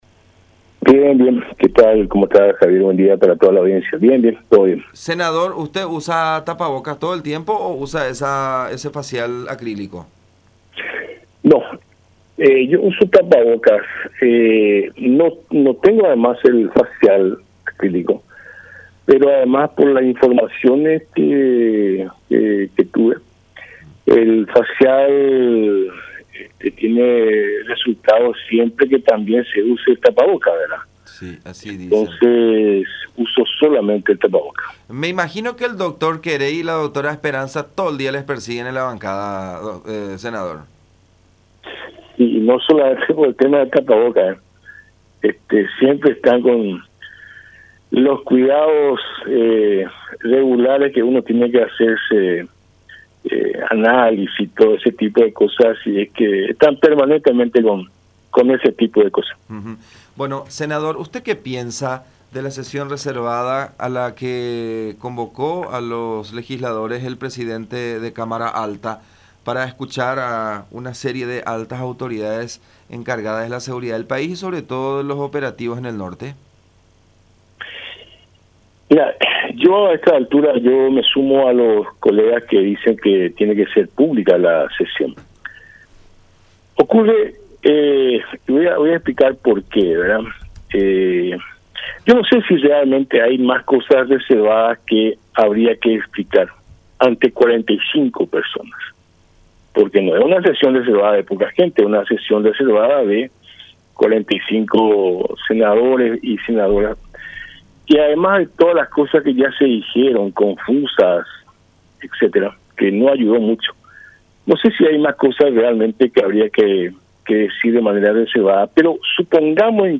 “Yo me sumo a los colegas que piden que esa sesión tiene que ser pública. Se dijeron muchas cosas que, en vez de ayudar, confundieron más. El enfoque es que toda esta situación que sucedió en Yby Yaú el gobierno lo manejó muy mal”, manifestó Richer en conversación con La Unión.